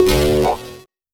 Blip 002.wav